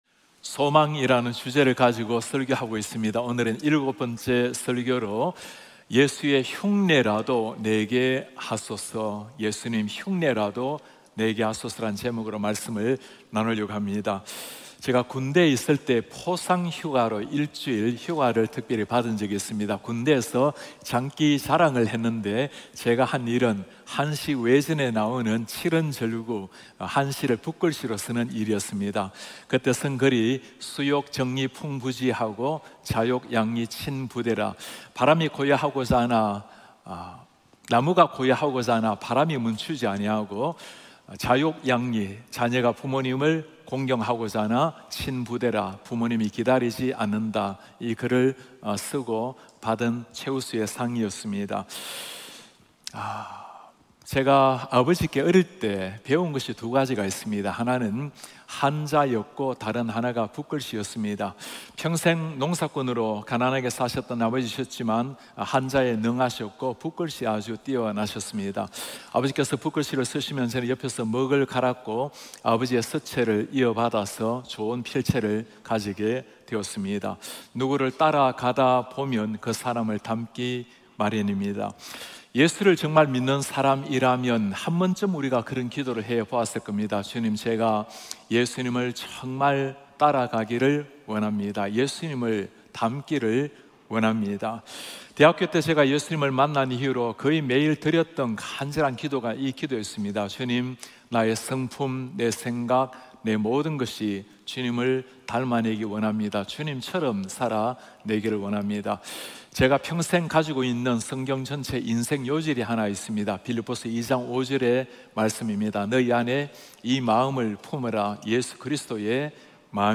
예배: 주일 예배